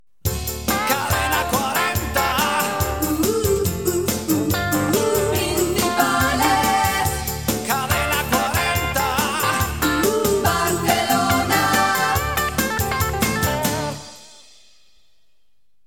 Indicatiu "Rock" de l'emissora
FM